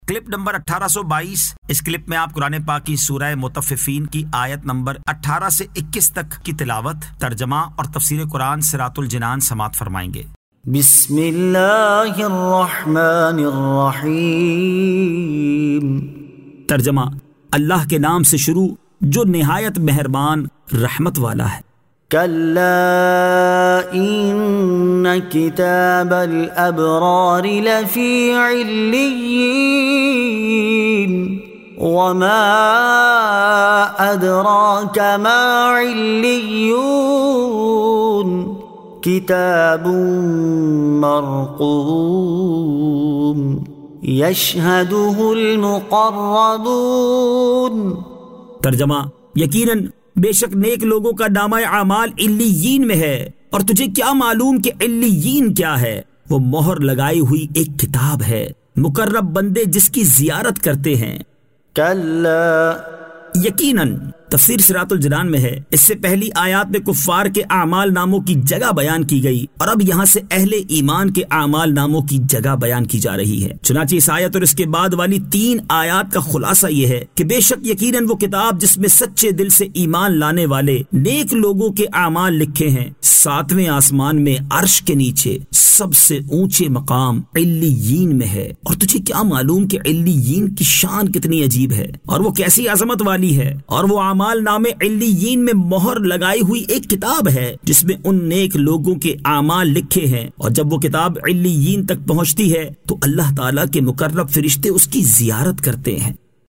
Surah Al-Mutaffifeen 18 To 21 Tilawat , Tarjama , Tafseer